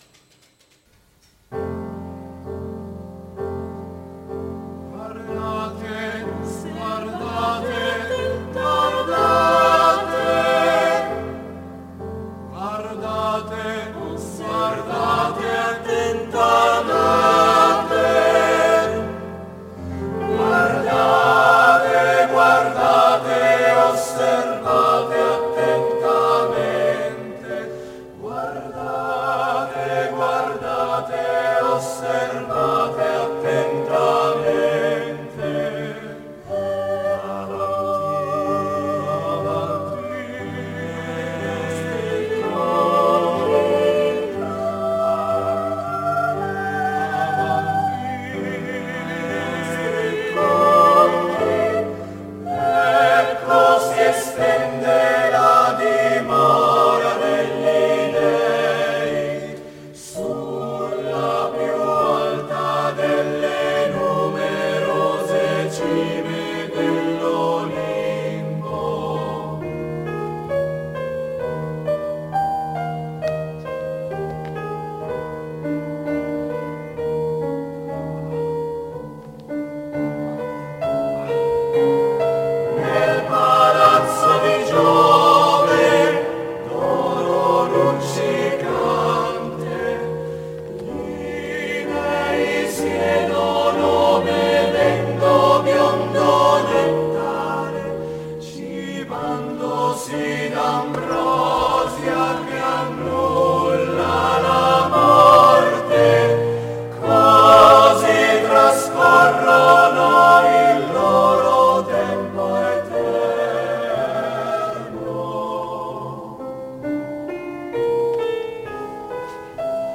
3 coro